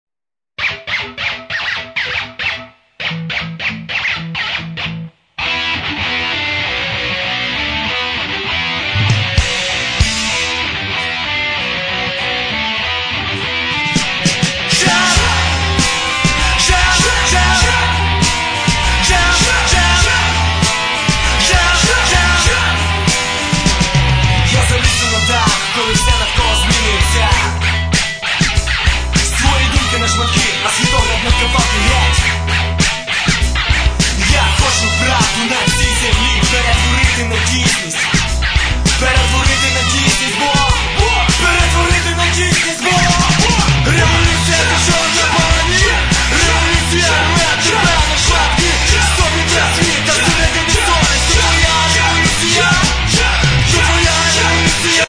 Рок (320)